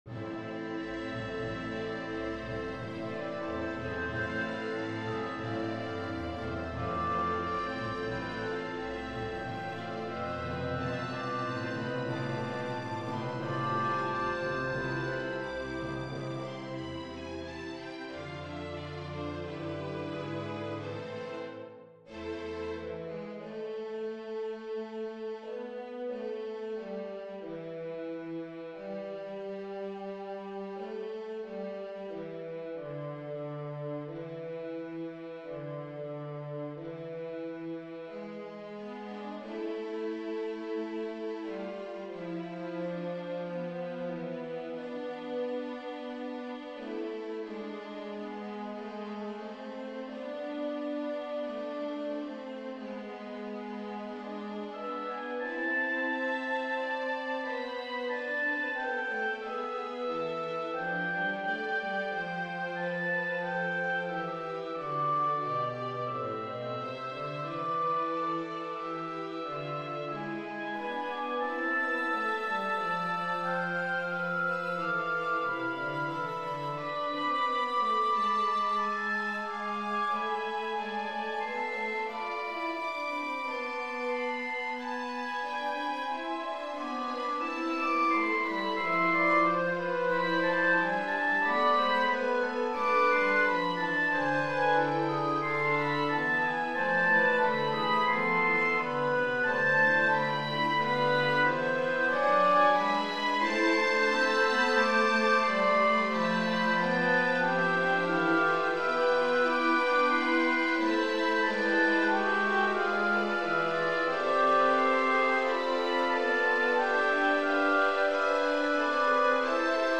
So, I've started a new orchestral work. The idea behind this is to musically capture my experience studying the cemeteries here in Central Illinois.